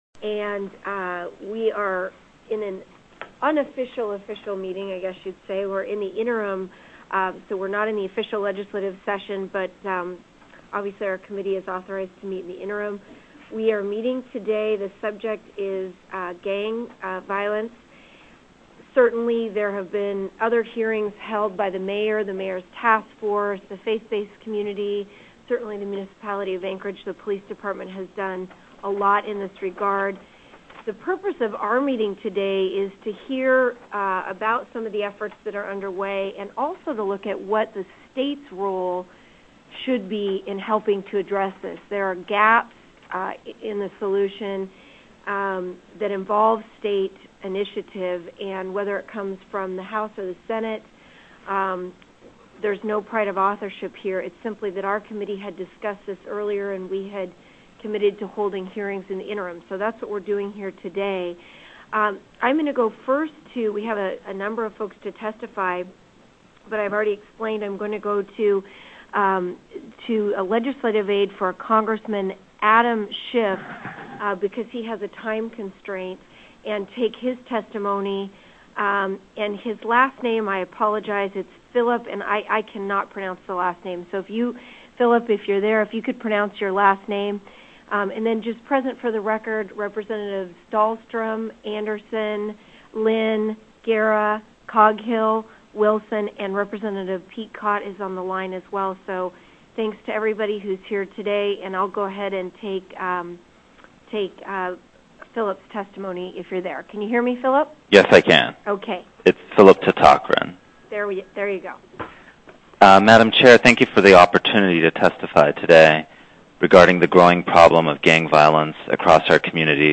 Legislature(2005 - 2006) Anch LIO Conf Rm 10/24/2006 10:00 AM House JUDICIARY play pause stop audio video House JUD 10/24/2006 10:12 Download Mp3.